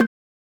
per3c - MidTom.wav